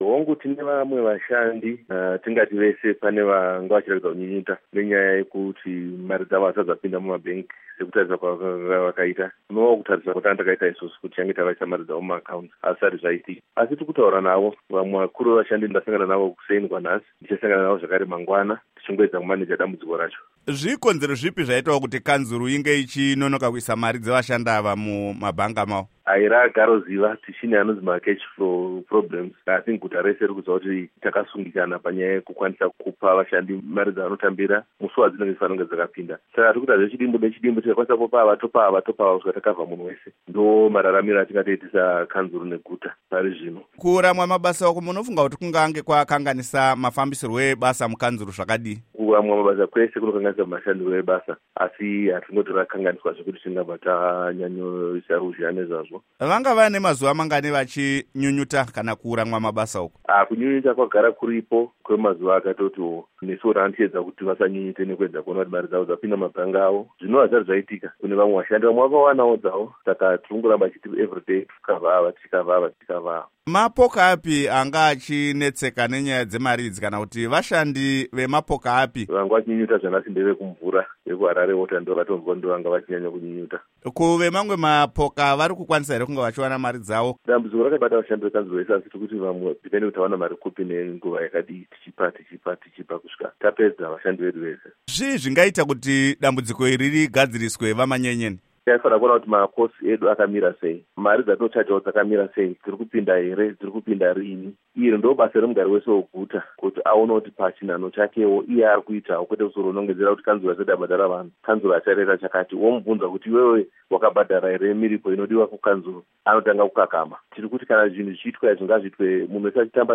Hurukuro naVaBernard Manyenyeni